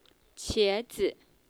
so even the male dialogue has female voice.